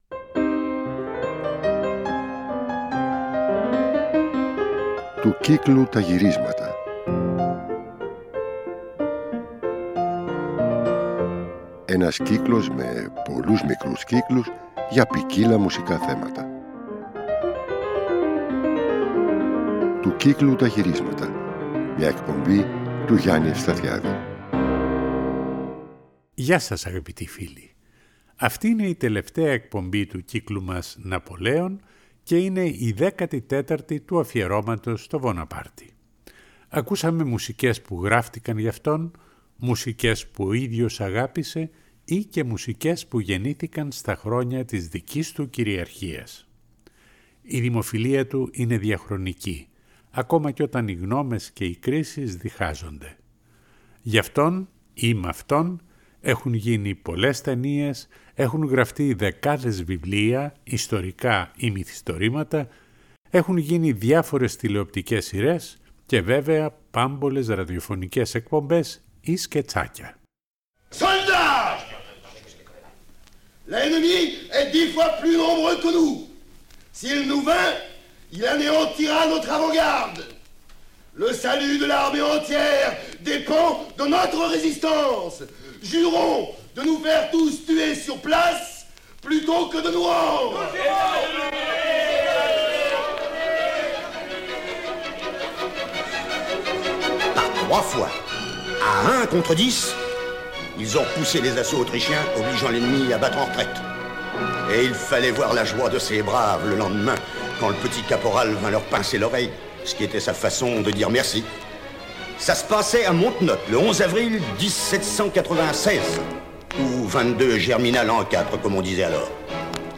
Η εκπομπή έχει ποικιλλία ακροαμάτων: ραδιοφωνικά σκετσάκια, απόσπασμα από audio book για τον Βοναπάρτη αλλά και γαλλικά παραδοσιακά τραγούδια για τον ίδιο και για τον ανηψιό του, ο οποίος διατέλεσε και πρόεδρος της δημοκρατίας και αυτοκράτορας.
Παράλληλα θα ακουστούν τραγούδια με κιθάρα – όργανο που έγινε ιδιαίτερα δημοφιλές στα χρόνια του Ναπολέοντα.